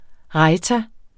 Udtale [ ˈʁɑjta ]